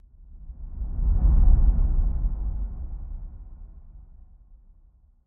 planet_reveal.mp3